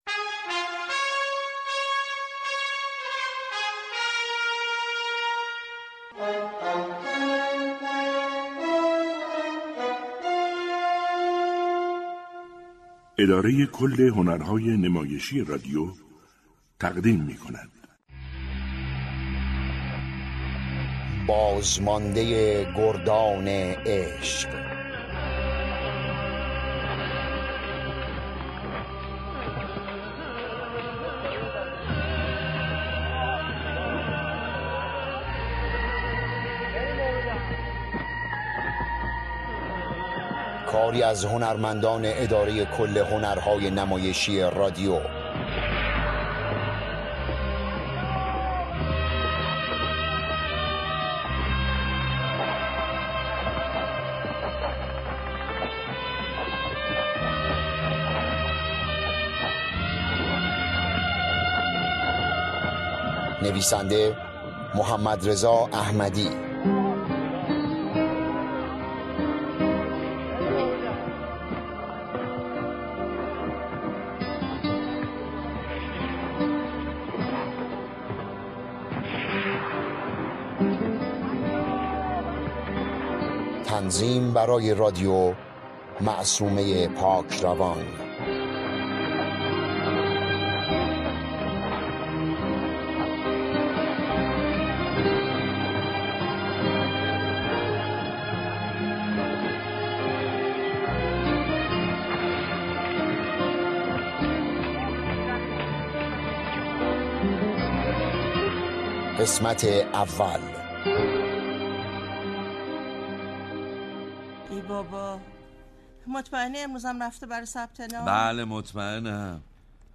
نمایش رادیویی